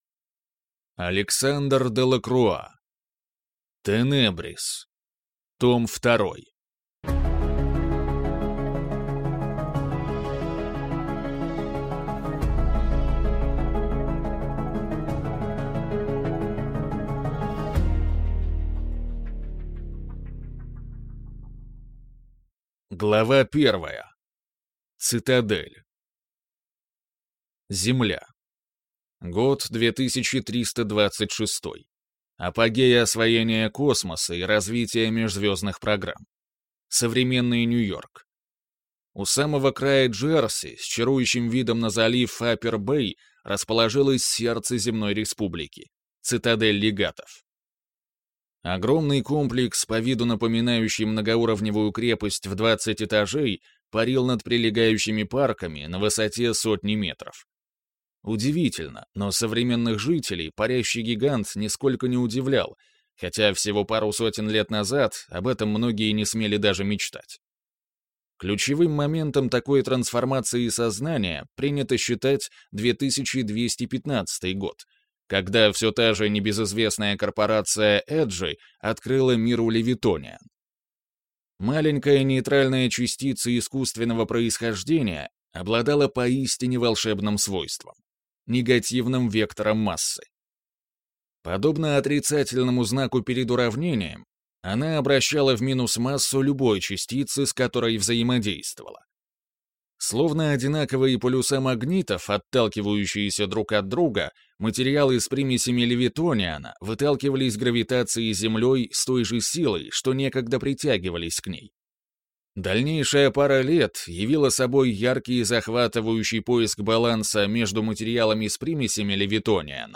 Аудиокнига Тенебрис. Том 2 | Библиотека аудиокниг